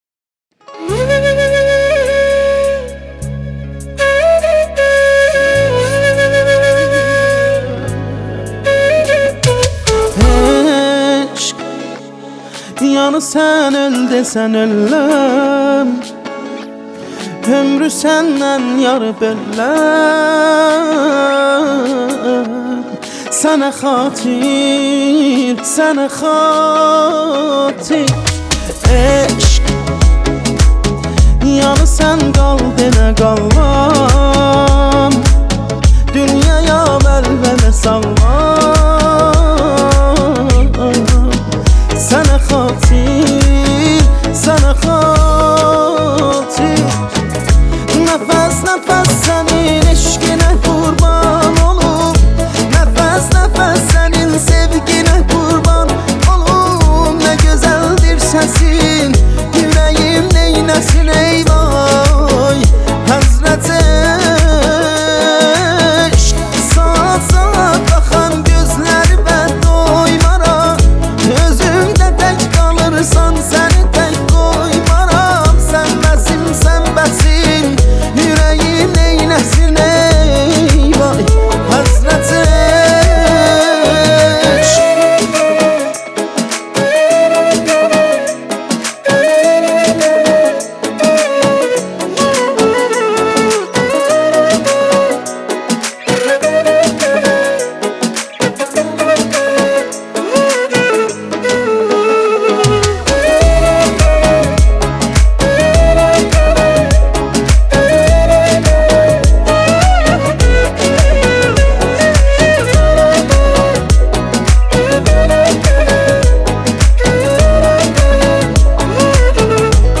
عاشقانه و ملایم